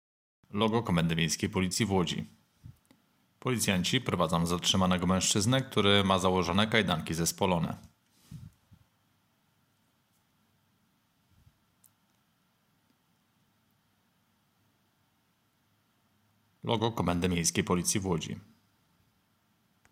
Nagranie audio deskrypcja_filmu.m4a